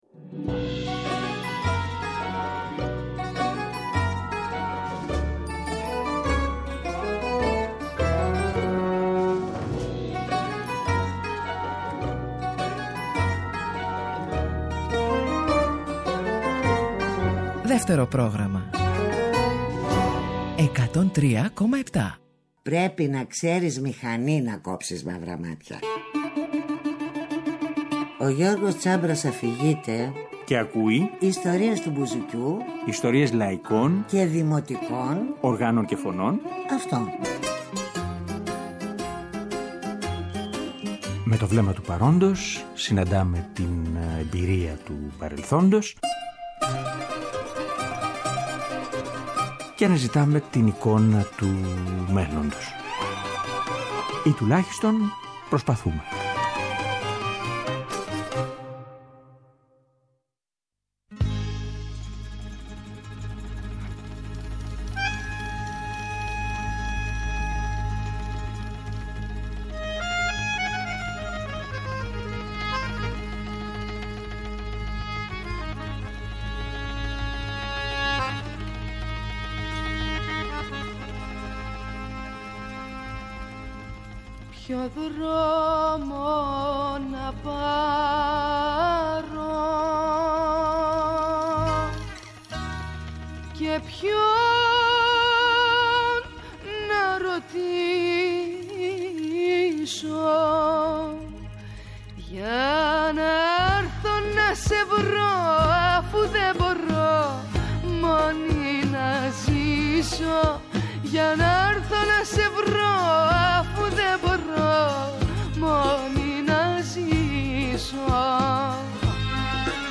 ηχογραφεί δυο ζεϊμπέκικα κι ένα χασάπικο